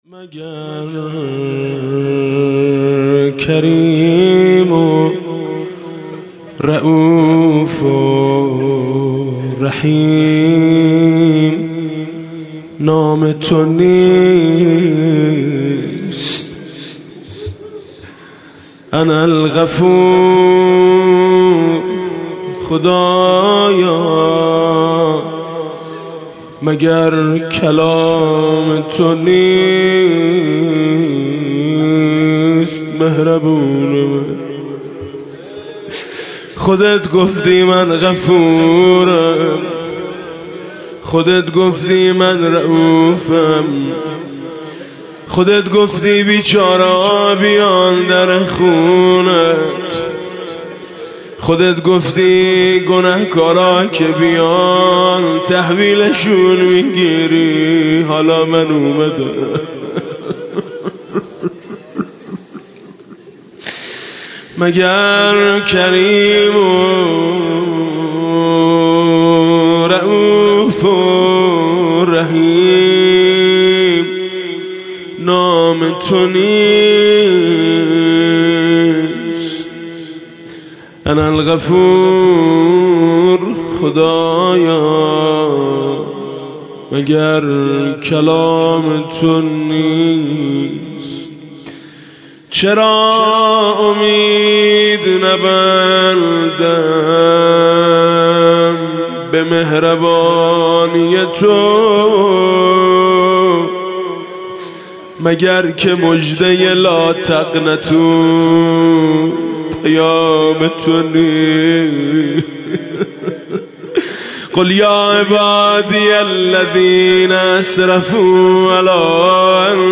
دانلود مناجات خوانی حاج میثم مطیعی – شب هفتم ماه مبارک رمضان۹۷
تو را به عفو خودت بازخواست خواهم کرد (مناجات با خدا)